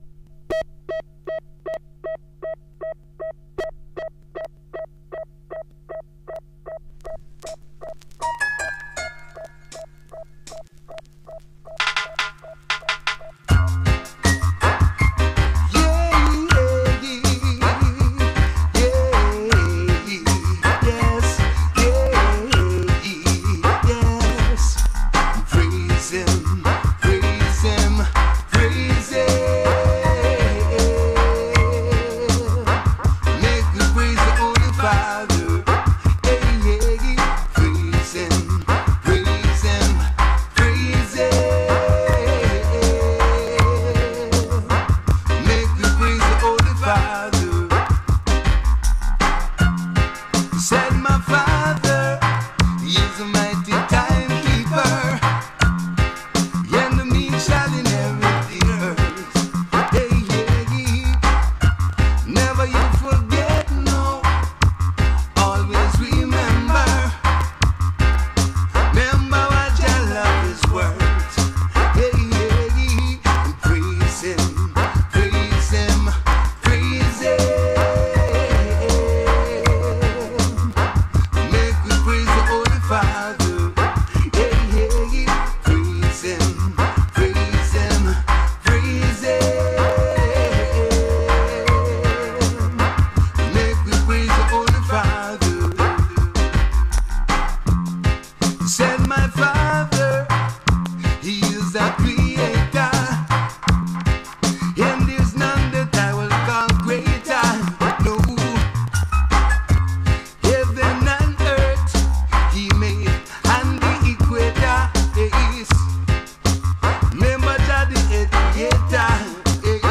Roots & Dub Sound System